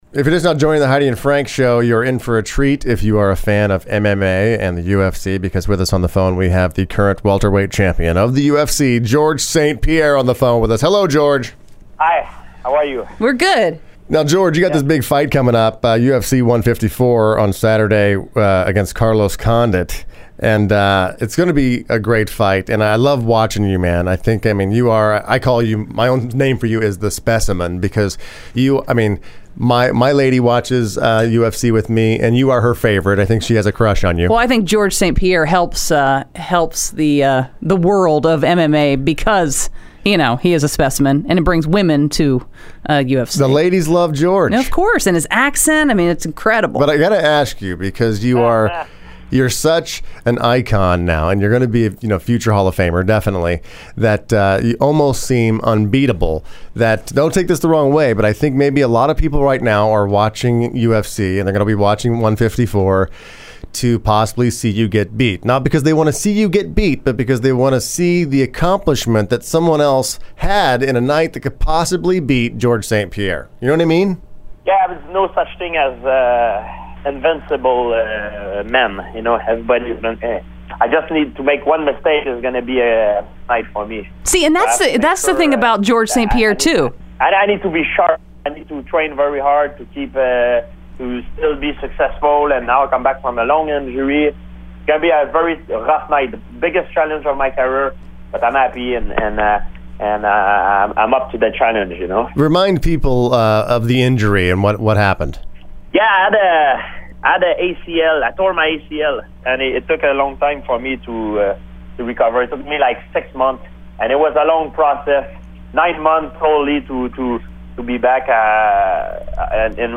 Listen November 12, 2012 - Interview - Georges St. Pierre - The Heidi & Frank Show